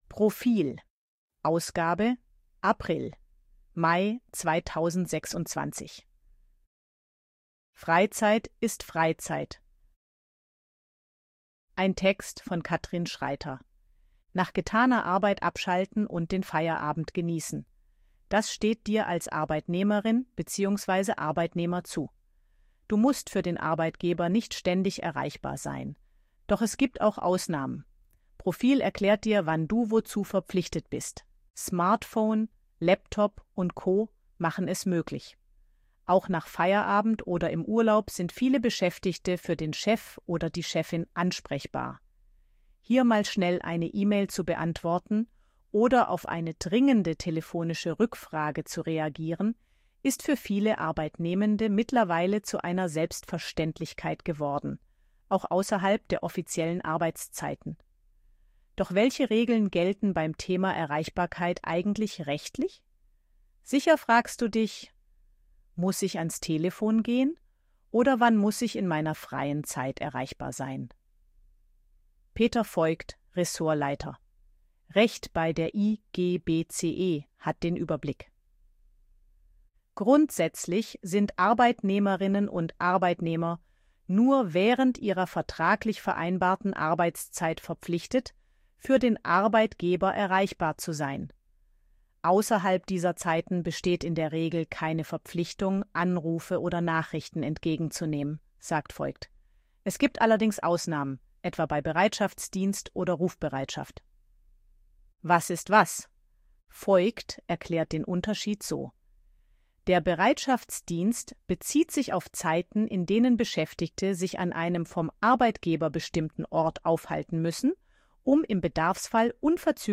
ElevenLabs_262_KI_Stimme_Frau_Service_Arbeit.ogg